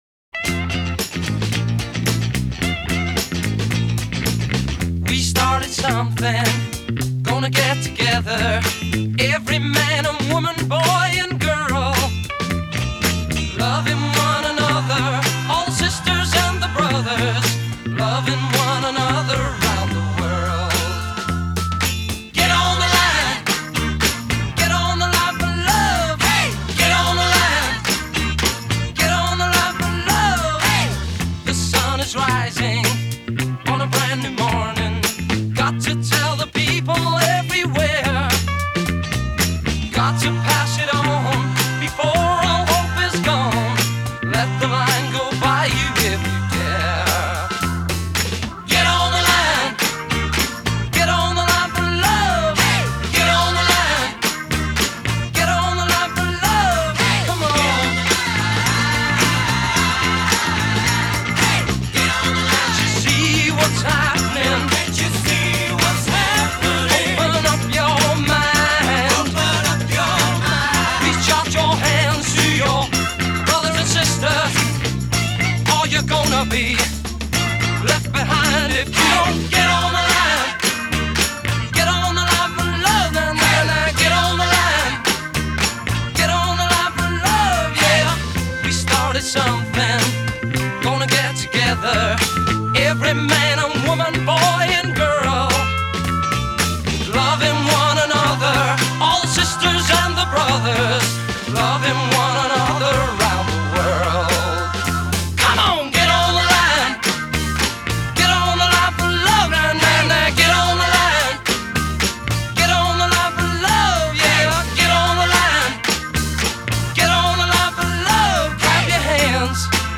Genre: Classic Rock